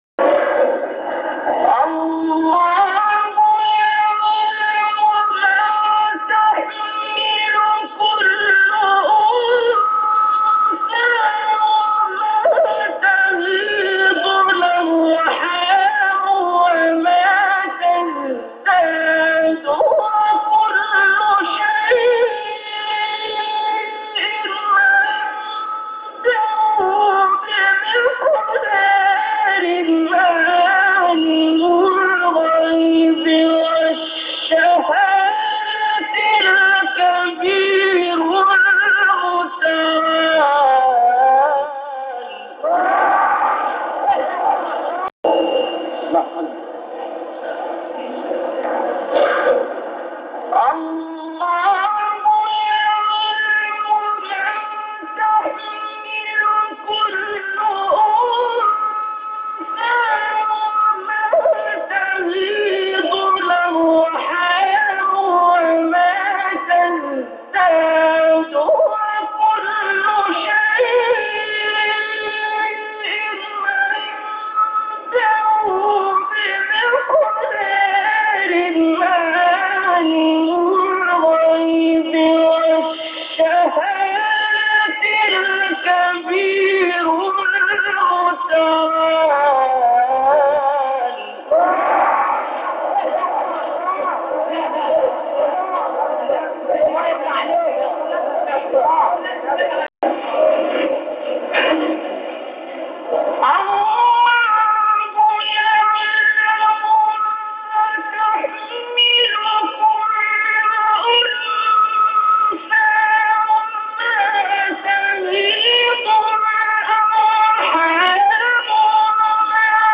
گروه فعالیت‌های قرآنی: مقاطع صوتی دلنشین از قراء بین‌المللی جهان اسلام را می‌شنوید.